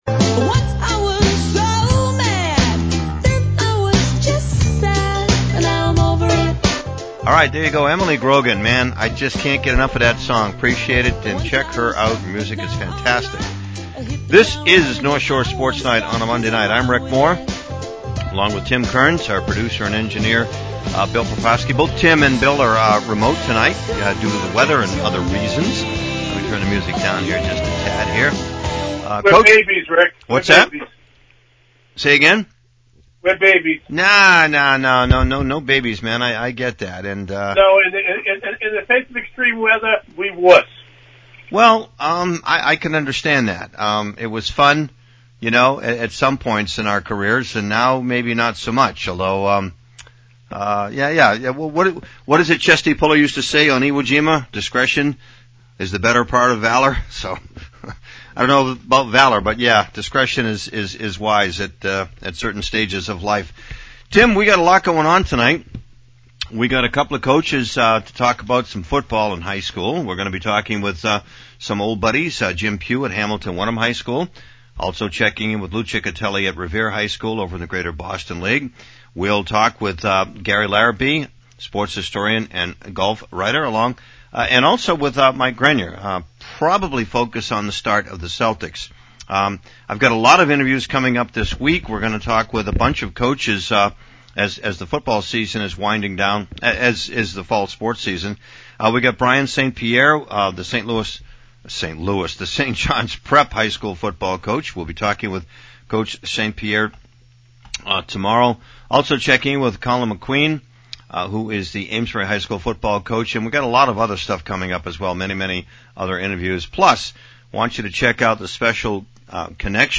LIVE Broadcast Tonight at 7 p.m.: North Shore Sports Night – Coaches and Sports Writers